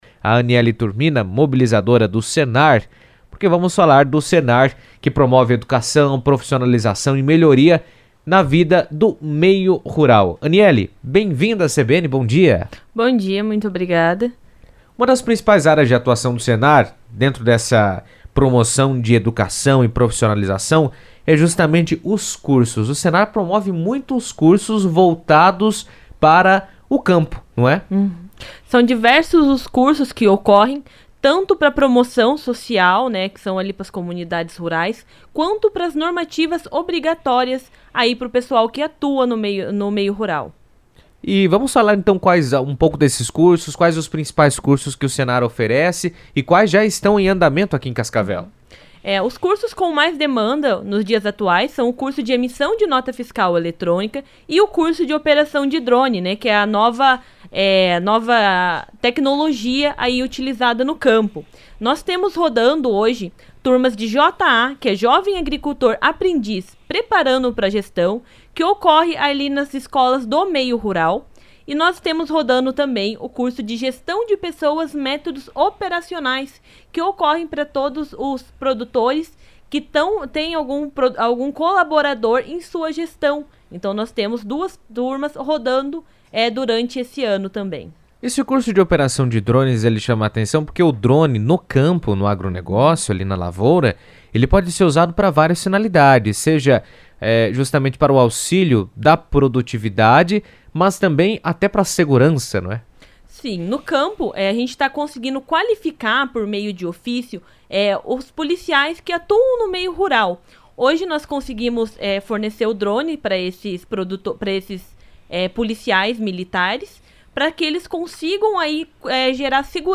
O Serviço Nacional de Aprendizagem Rural (Senar) está com vagas abertas para diversos cursos gratuitos voltados à qualificação de produtores e trabalhadores do meio rural. Em entrevista à CBN